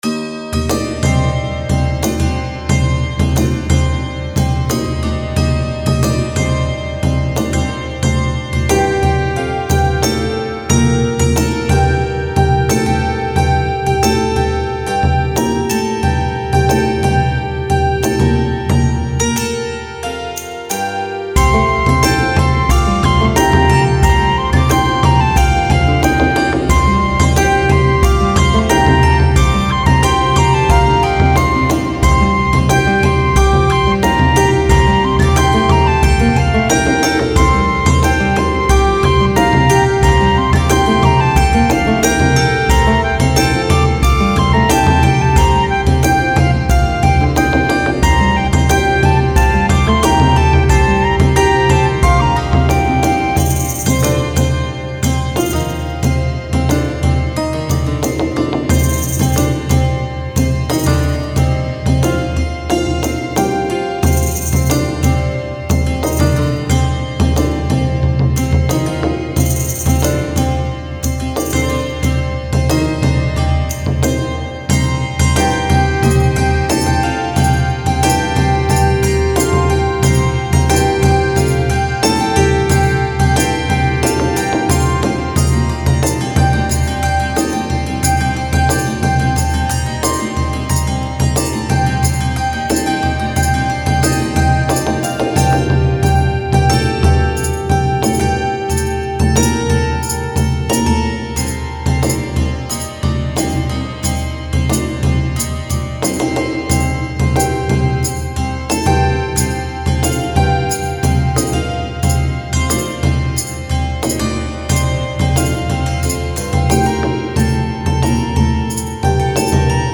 Medieval Fantasy Music